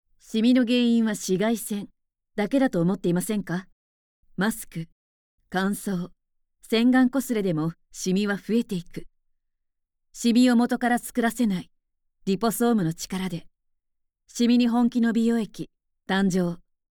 I have a resonant voice with low to mid tones. I am good at narration with a sense of transparency and narration with overtones.
– Narration –
Low-voiced, stately.